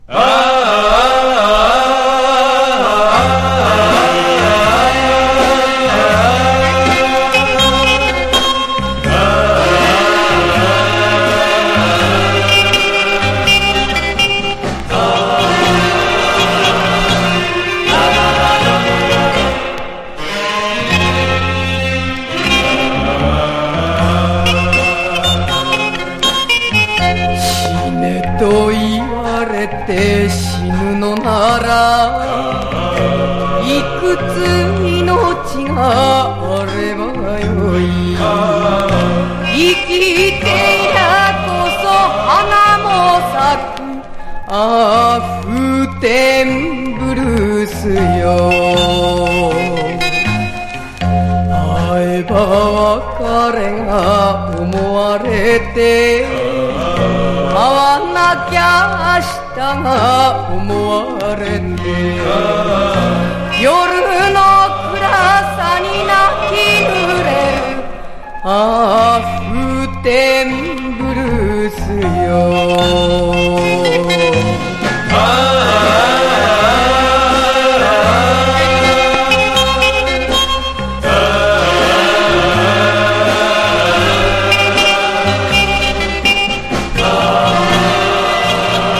和モノ / ポピュラー